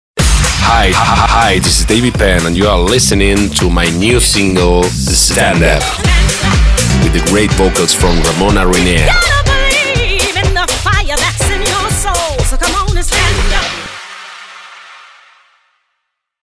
Imaging
Sweeper